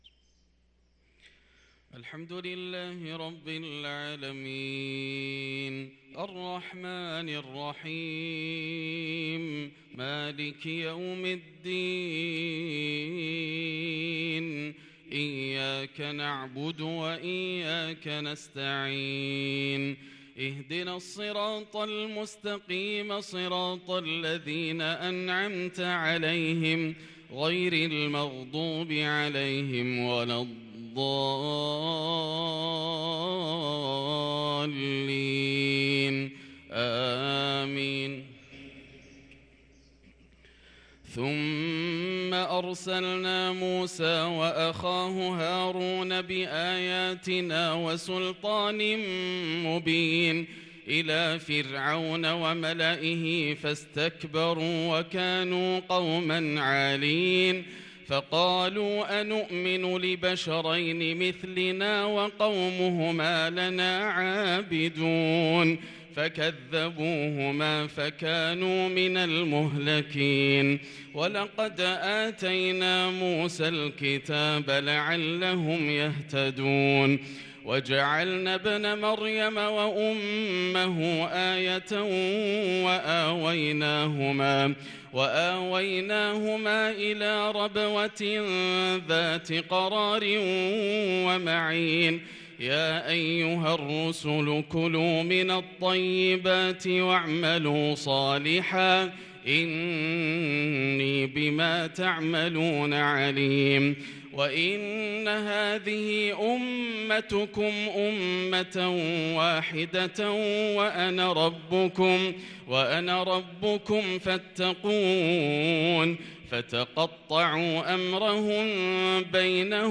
صلاة الفجر للقارئ ياسر الدوسري 24 شعبان 1443 هـ